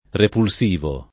repulsivo [ repul S& vo ]